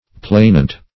Search Result for " plainant" : The Collaborative International Dictionary of English v.0.48: Plainant \Plain"ant\, n. [See 1st Plain .] (Law) One who makes complaint; the plaintiff.